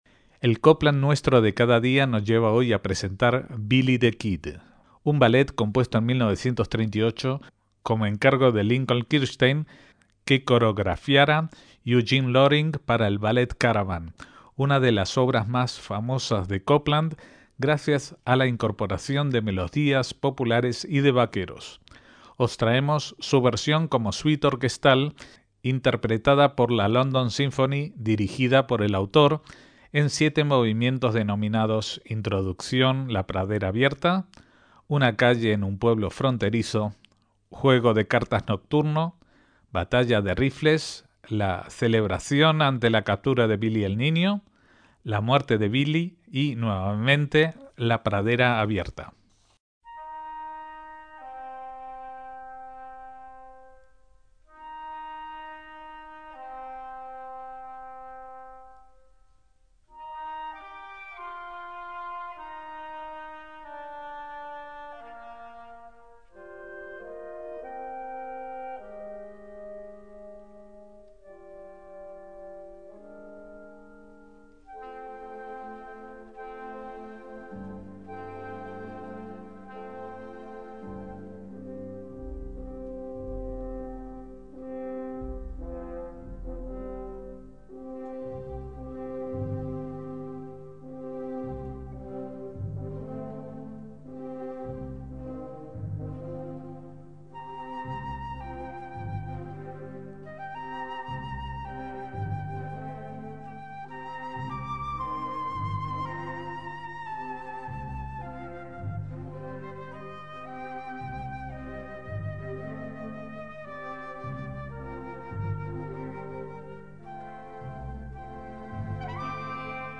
ballet
melodías folk de los vaqueros